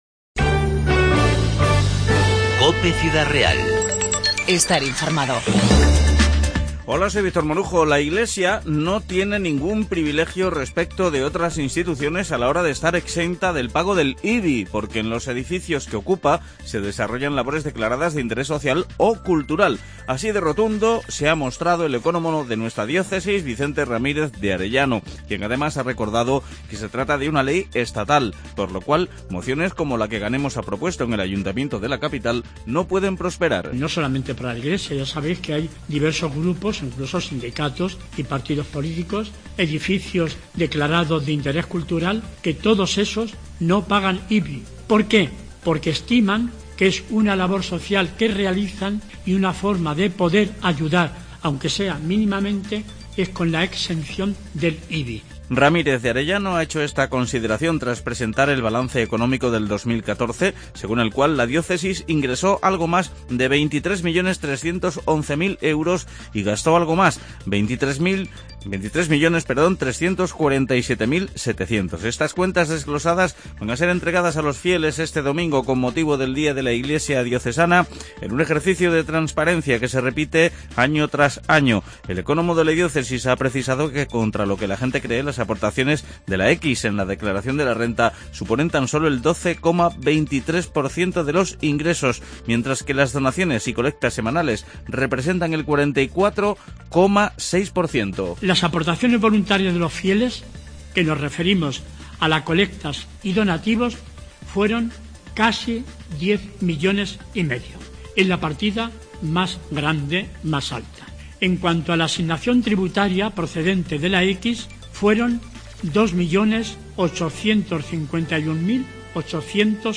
INFORMATIVO 12-11-15